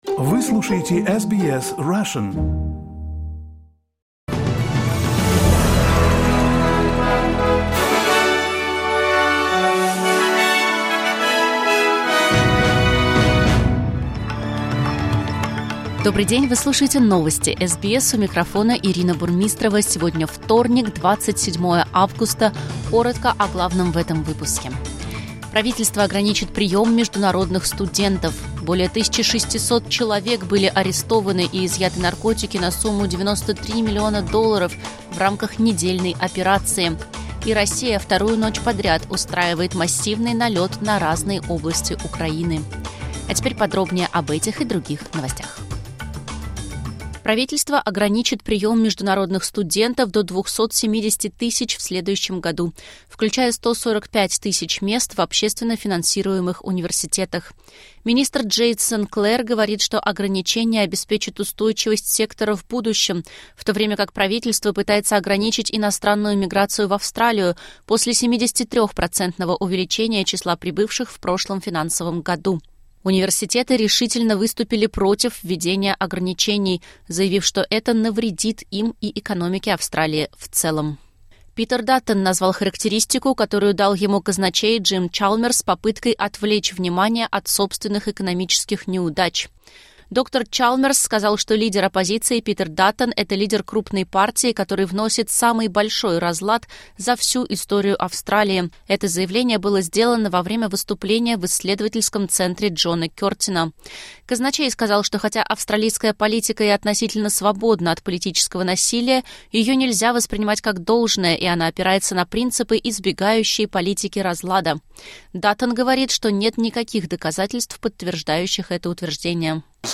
Новости SBS на русском языке — 27.08.2024